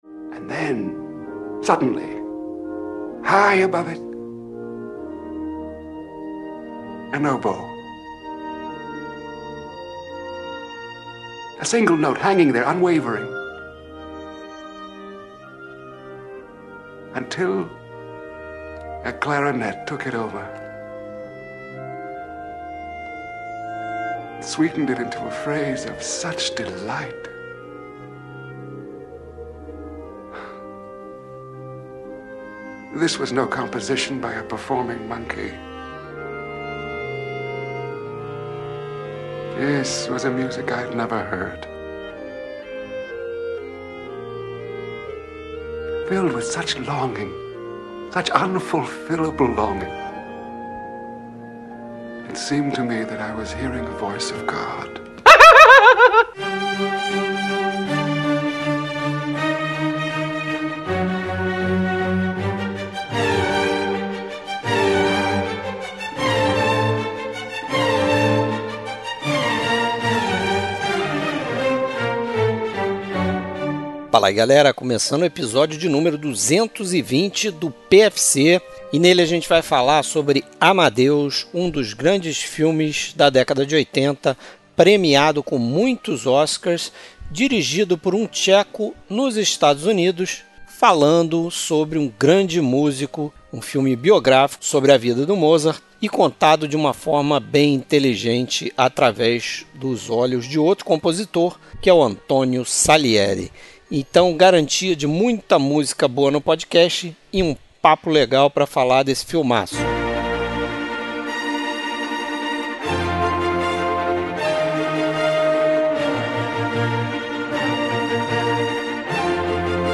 Entre outros, o clássico do tcheco Forman ganhou melhor filme e diretor naquela noite de premiação. Trilha Sonora: trilha sonora que acompanha o filme.